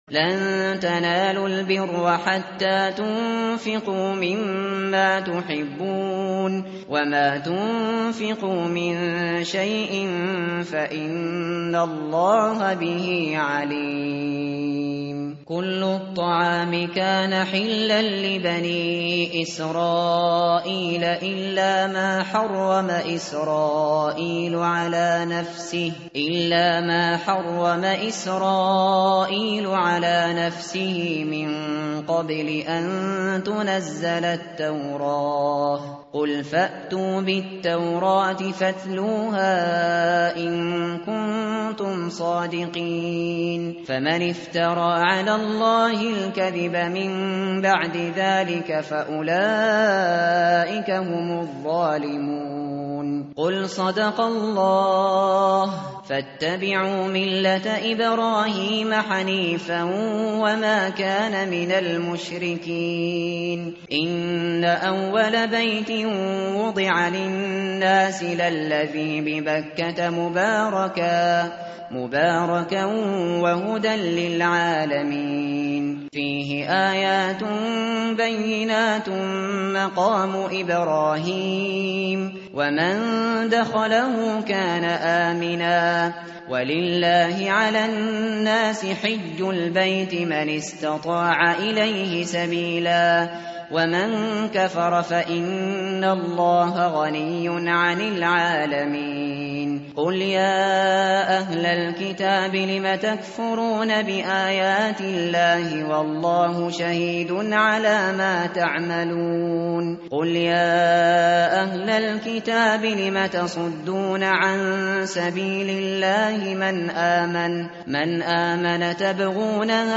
متن قرآن همراه باتلاوت قرآن و ترجمه
tartil_shateri_page_062.mp3